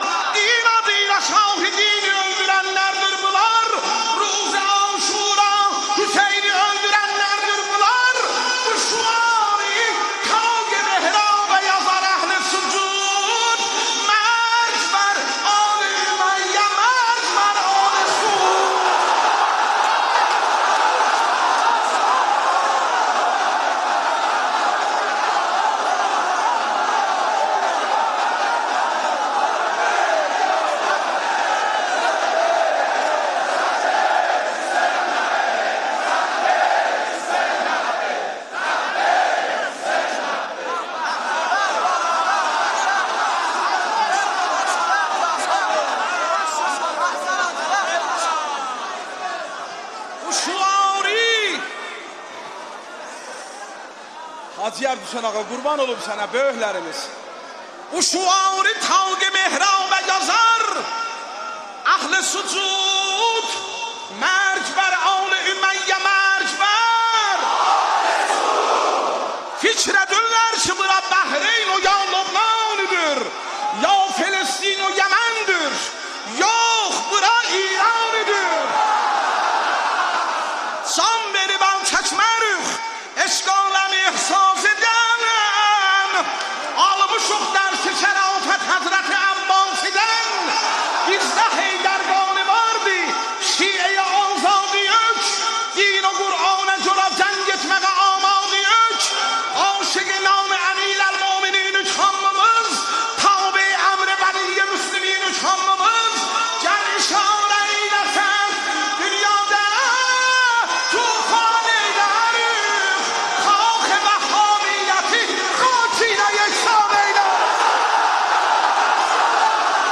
رجز خوانی ماه محرم مداحی آذری نوحه ترکی